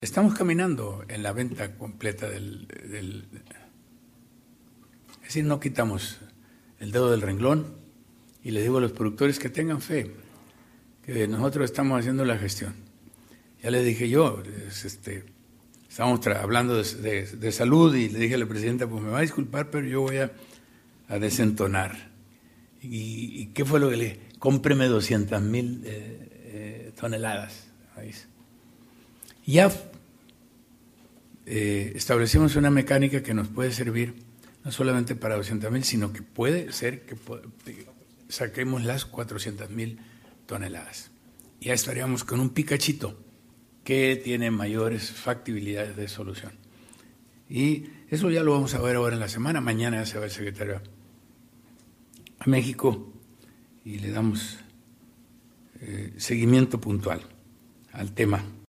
Culiacán, Sinaloa, a 21 de julio de 2025.- Durante su Conferencia Semanera del gobernador Rubén Rocha Moya, dio a conocer que hizo una propuesta a la presidenta de la República, Claudia Sheinbaum, para obtener un apoyo de la Federación y destinarlo a la comercialización de 400 mil toneladas de maíz que están pendientes de colocación, petición que encontró eco y por ello este martes el secretario de Agricultura, Ismael Bello Esquivel acudirá a la Ciudad de México para darle seguimiento a esta propuesta en la SADER.